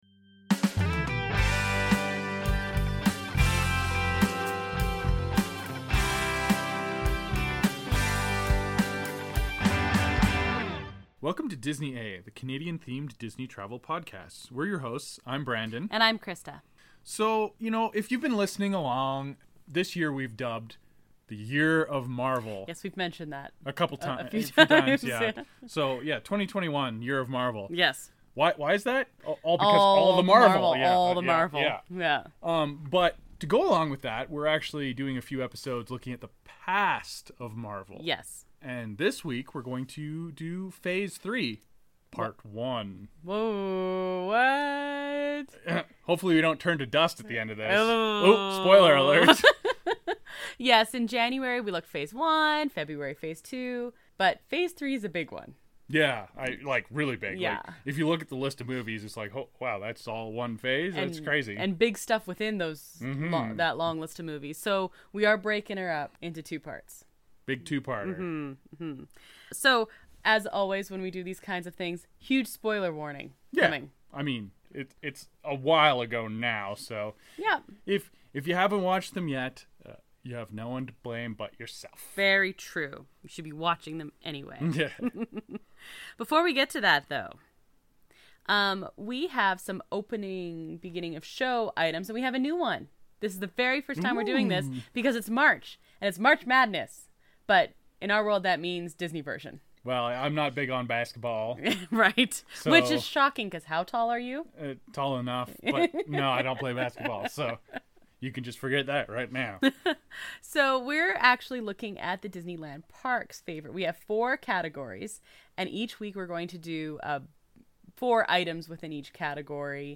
This week it's everything from Captain America: Civil War, up to Black Panther. There's some analysis, some giggling, and a whole lot of raving.